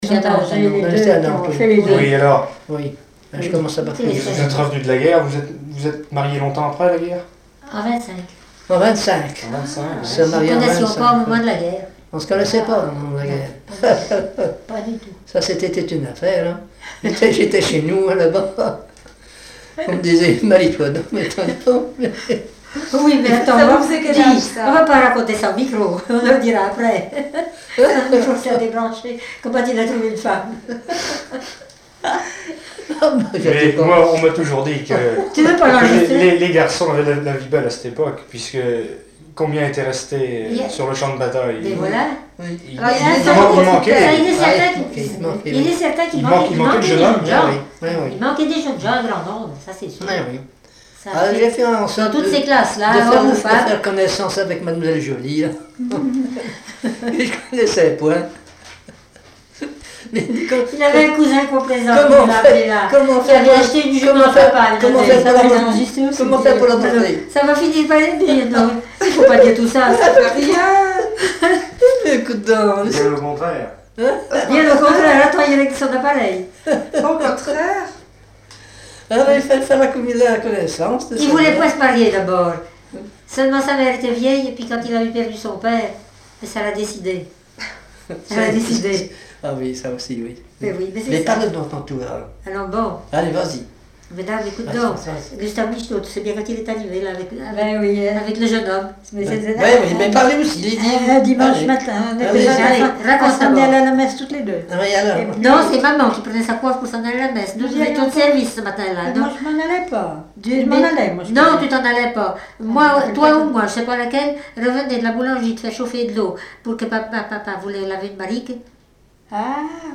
Témoignages sur la guerre 1914-14, histoire familiale
Catégorie Témoignage